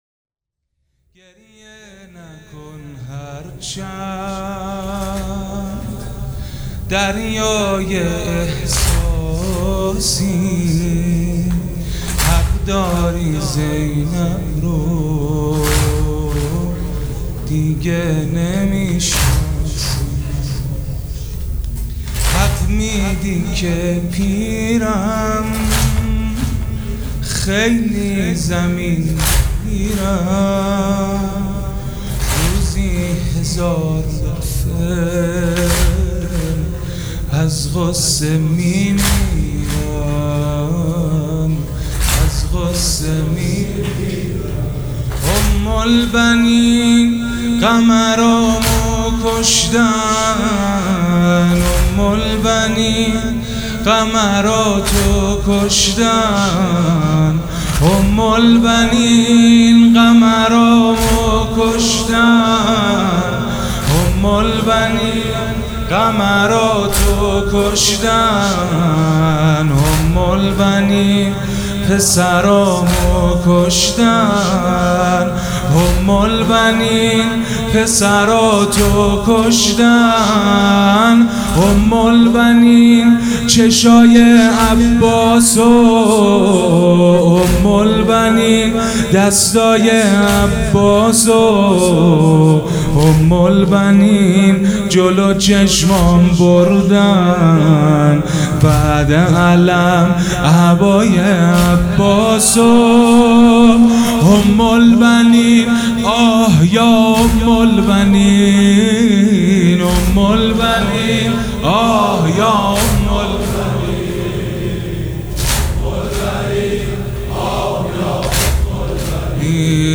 سبک اثــر واحد
وفات حضرت ام البنین (س)